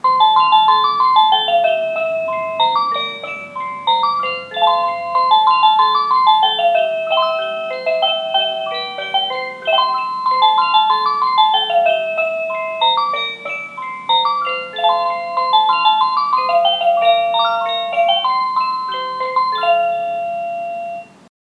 10-Cuckoo-Tune.mp3